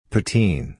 プーティン（英語発音puːˈtiːn/）は、フライドポテトにグレイビーソースと粒状のチーズカードをかけたファーストフード形式の食べ物。